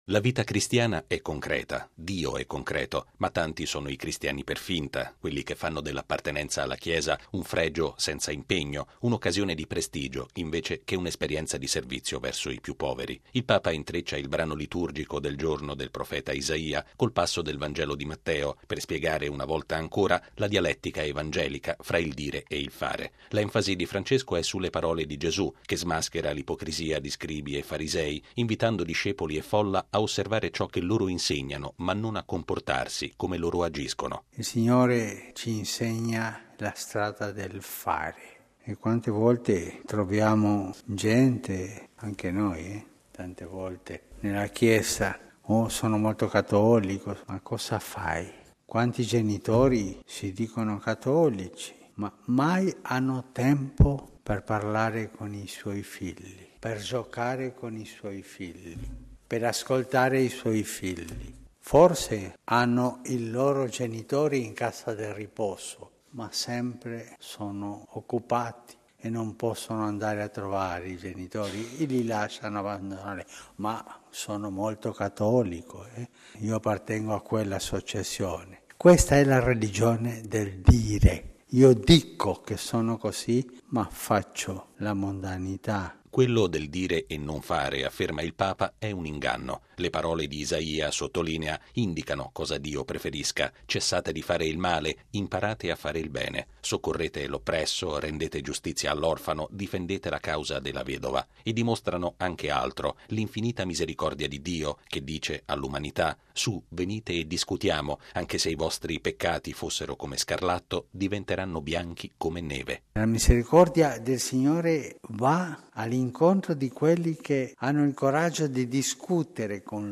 Quella cristiana è una religione concreta, che agisce facendo il bene, non una “religione del dire”, fatta di ipocrisia e vanità. Papa Francesco lo ha ripetuto commentando la liturgia del giorno all’omelia della Messa celebrata in Casa S. Marta.